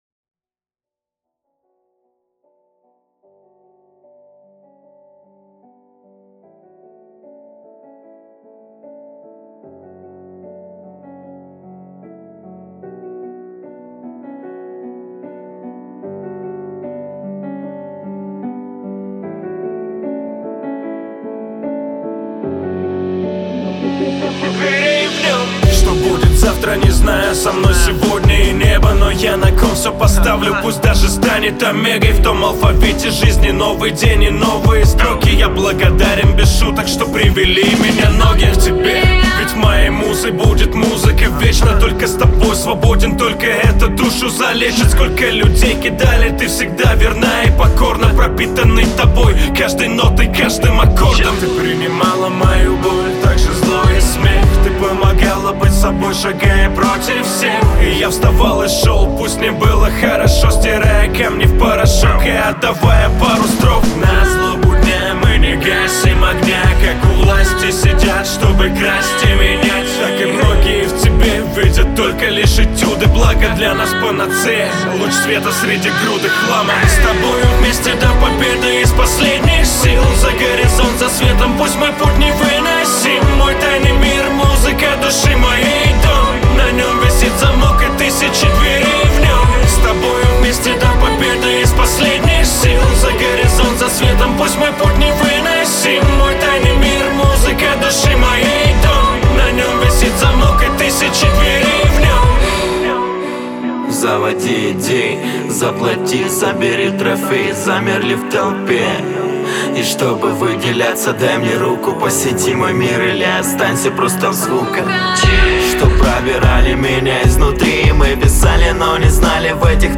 Плейлисти: Лірична музика, Поп, Російськомовна музика